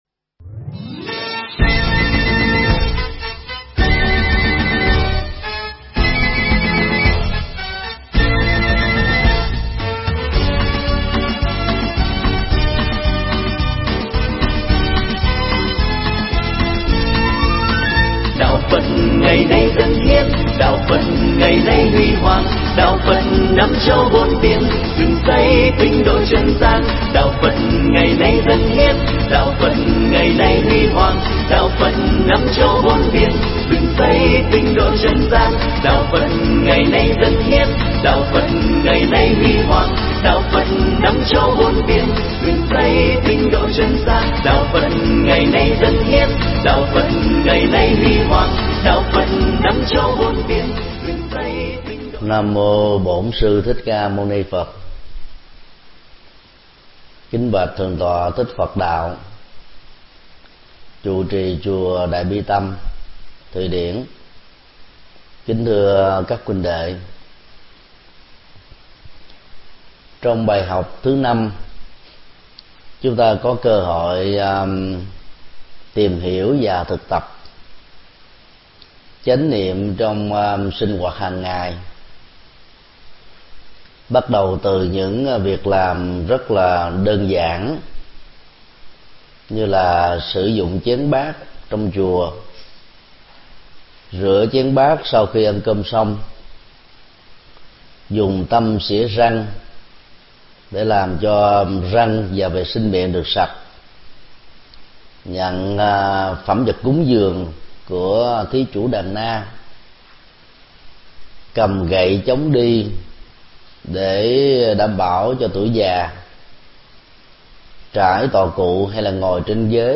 Mp3 Pháp Thoại Tỳ ni nhật dụng 05: Chánh niệm trong sinh hoạt
giảng tại chùa Đại Bi Tâm, Thụy Điển